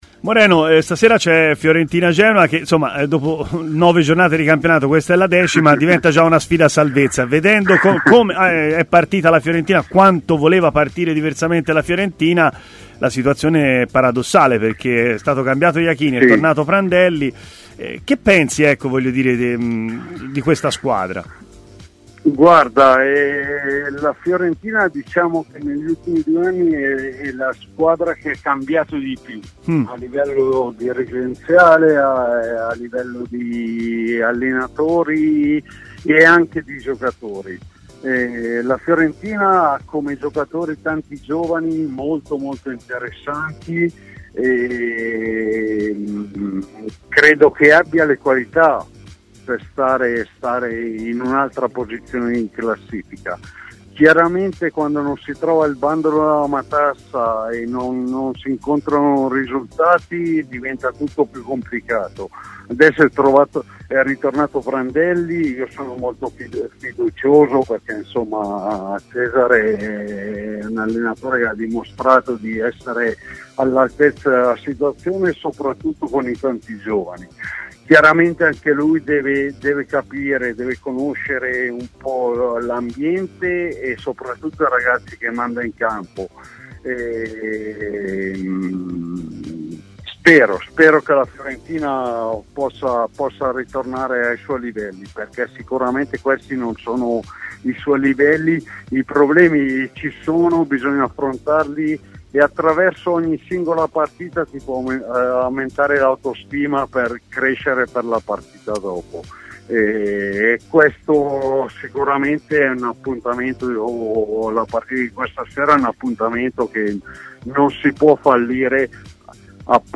L'ex difensore Moreno Torricelli è intervenuto a TMW Radio, nel corso della trasmissione Stadio Aperto.